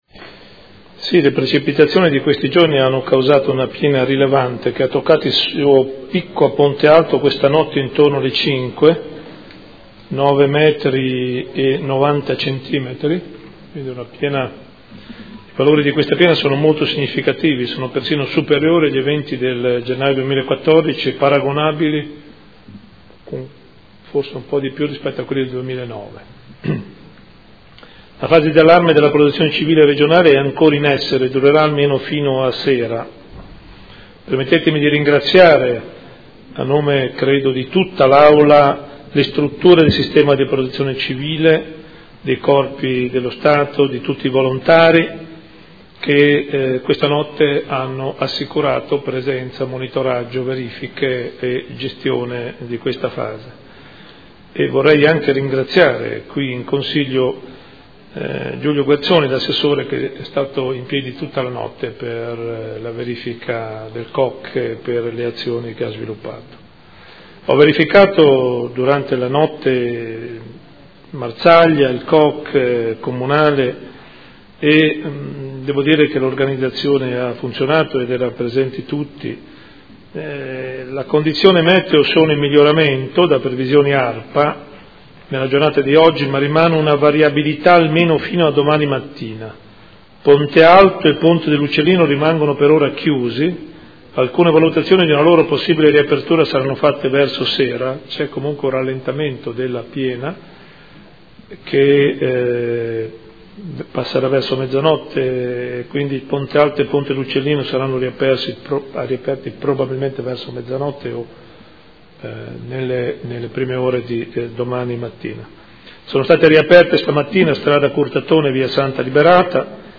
Seduta del 26/03/2015. Comunicazione del Sindaco sulla situazione dei fiumi